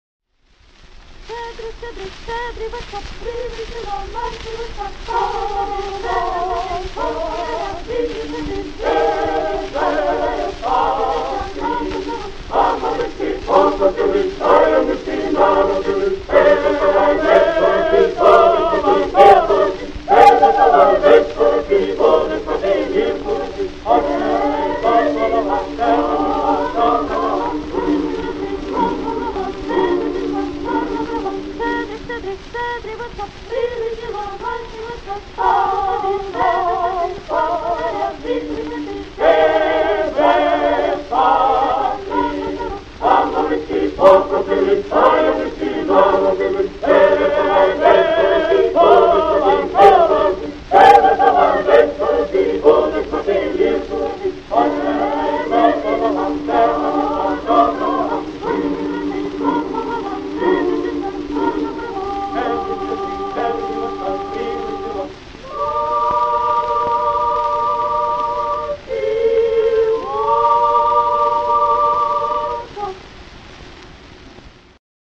Українська народна пісня
Архівний запис 1922 року.